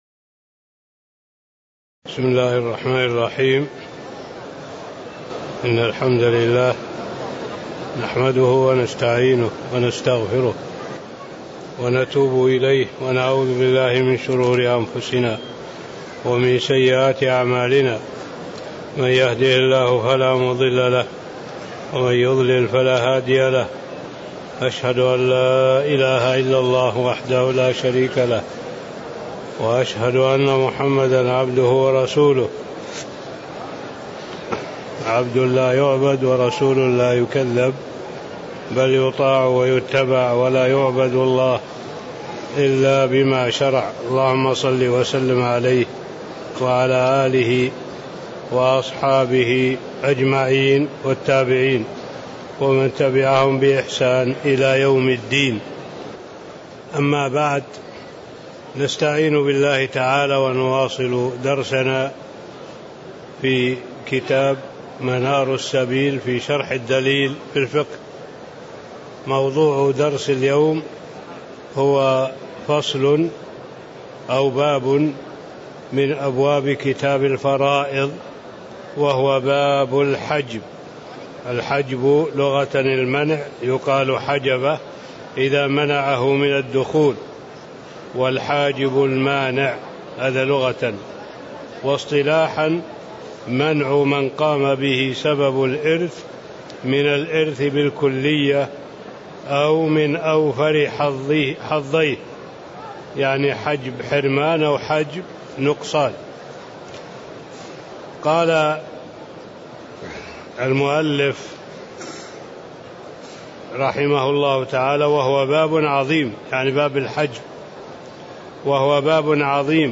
تاريخ النشر ٢٢ ربيع الثاني ١٤٣٧ هـ المكان: المسجد النبوي الشيخ